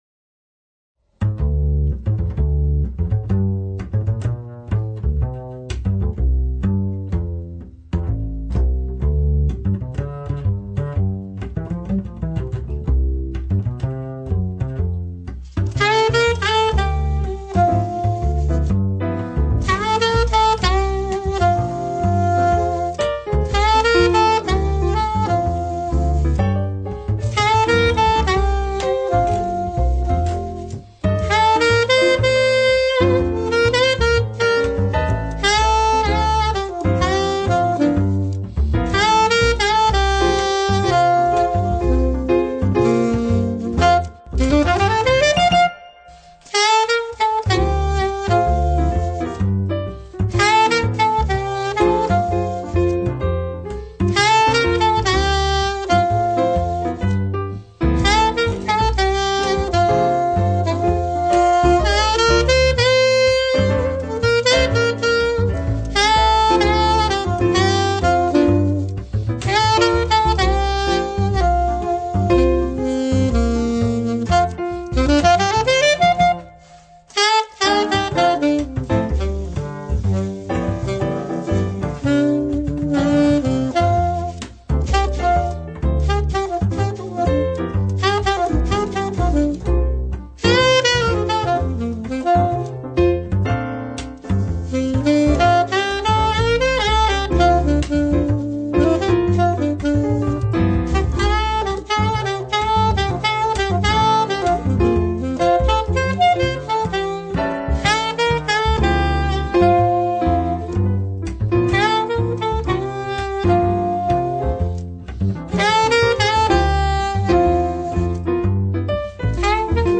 • Funk, Contemporary Tunes
Sax, Piano, Double Bass